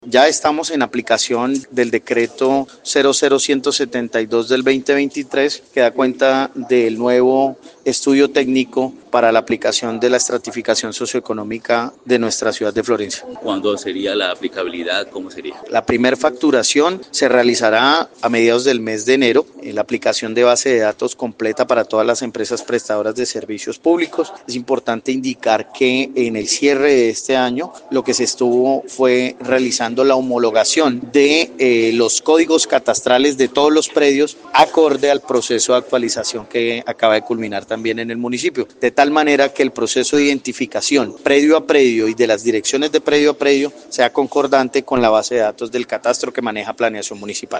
Luis Manuel Espinoza, secretario de planeación municipal explicó que, esto se da tras tener completa la homologación de los códigos catastrales de todos los predios a corde con la base de datos del catastro que maneja el municipio.